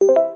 dingding.wav